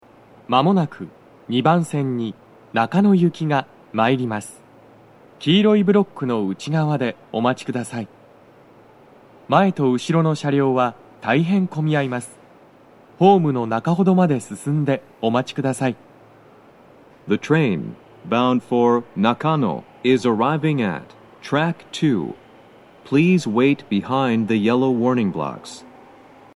スピーカー種類 BOSE天井型
2番線の鳴動は、やや遅めです。
男声
接近放送2